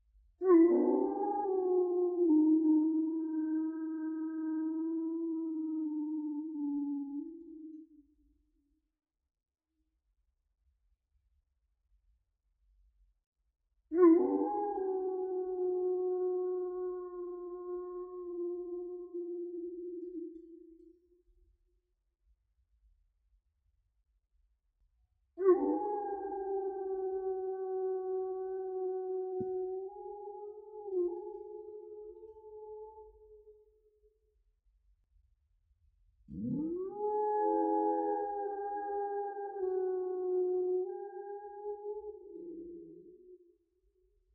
Wolf_howling-solo-winter.wav